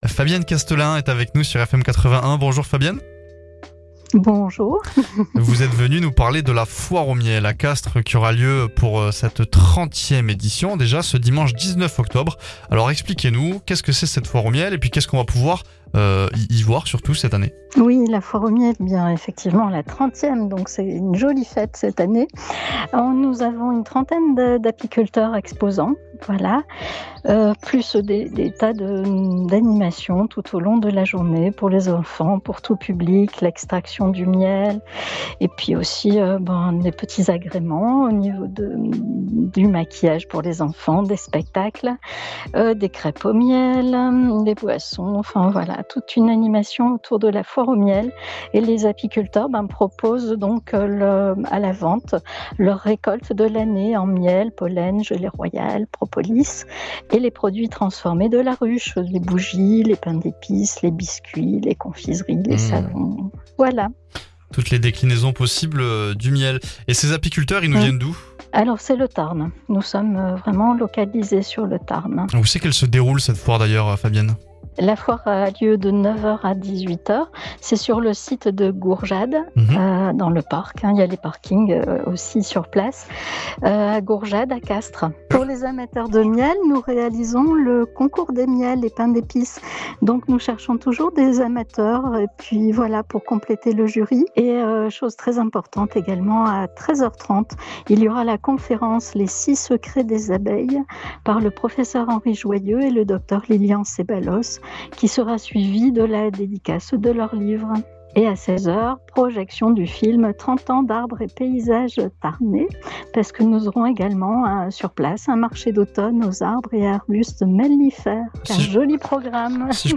LA VIE LOCALE DU TARN